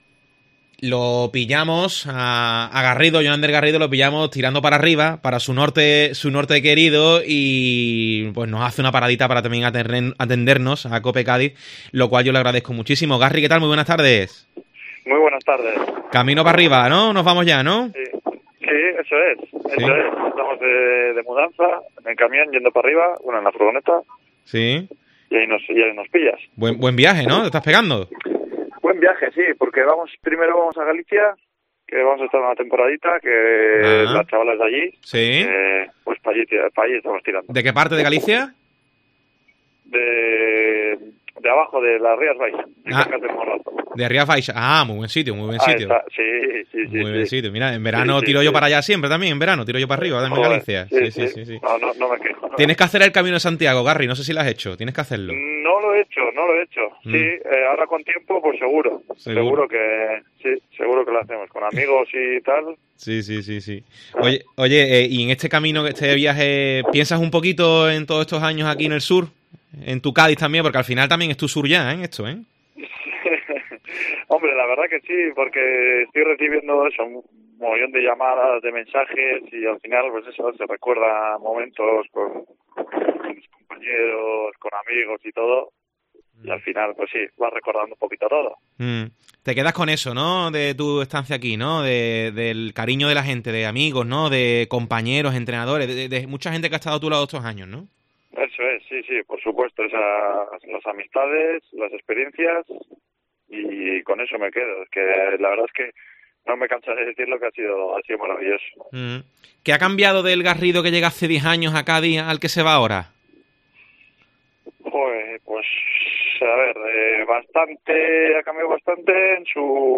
Álvaro Cervera y Salvi saludan al vasco en una entrevista en la que repasa sus diez años de carrera como cadista
ESCUCHA LA ENTREVISTA CON GARRIDO EN DEPORTES COPE